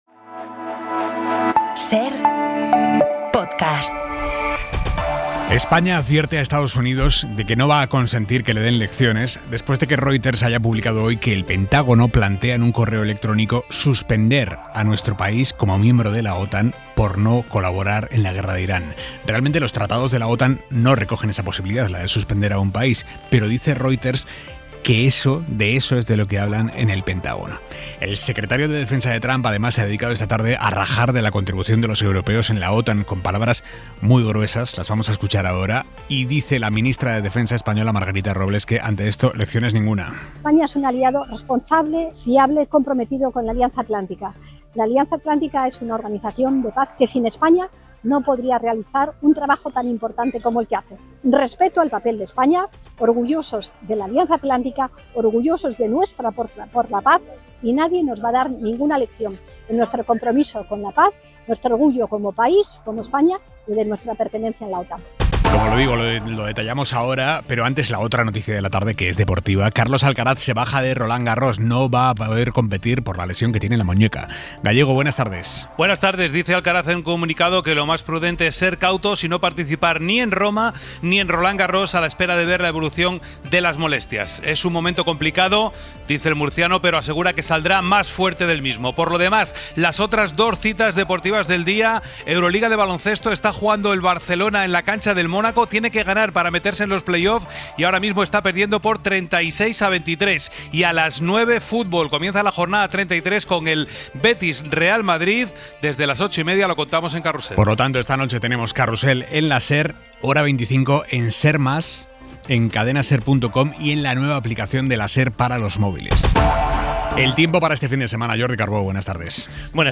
Las noticias que debes conocer esta tarde, con Aimar Bretos